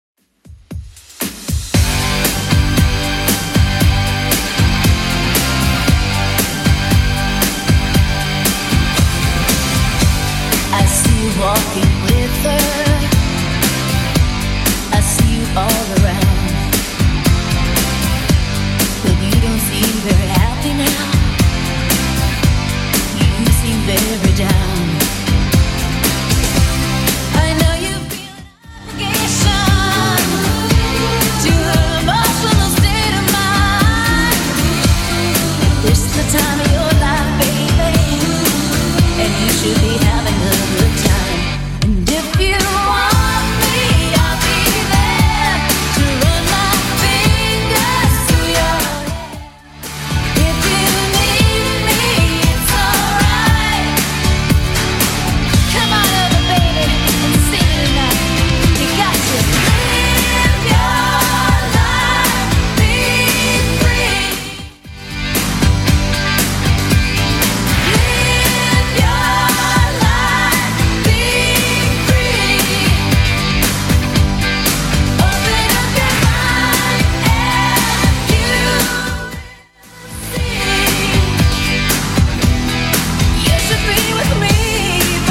BPM: 116 Time